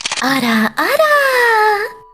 Worms speechbanks
CollectArm.wav